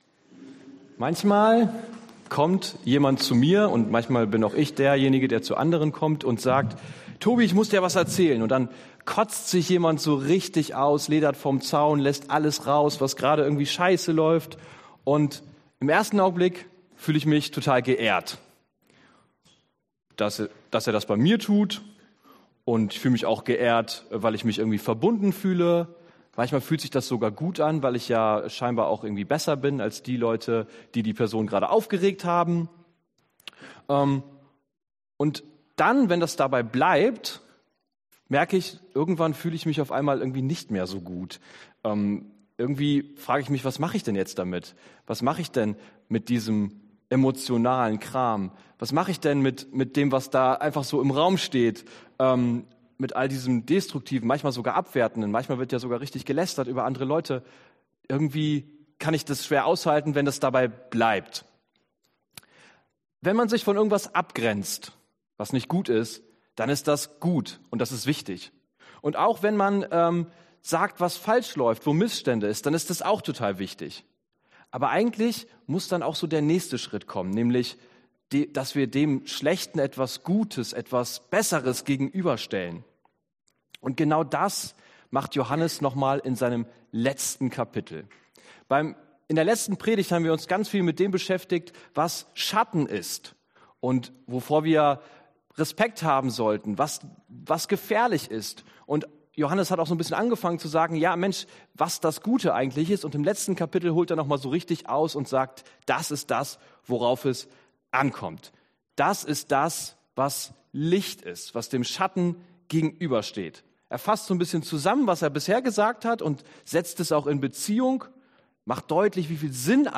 Johannes 5 Dienstart: Predigt « Schattenwelten Wie werde ich Christ?